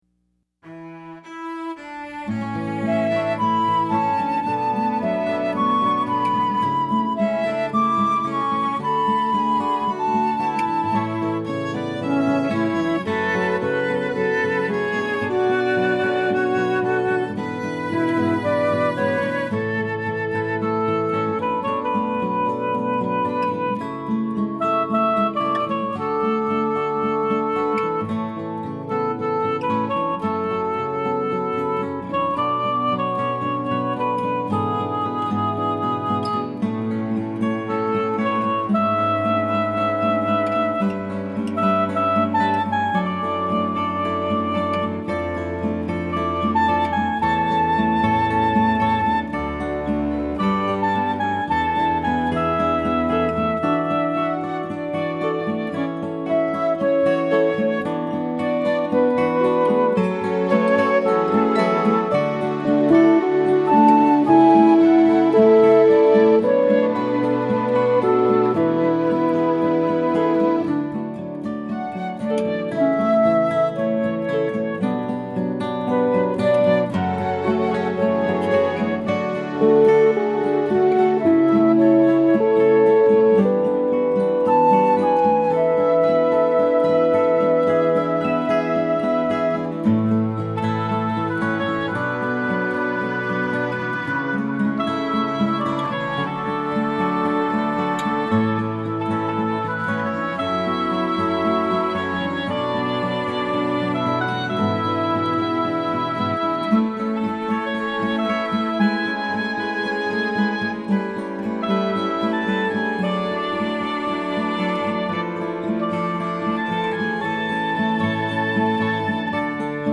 set-you-free-instrumental.mp3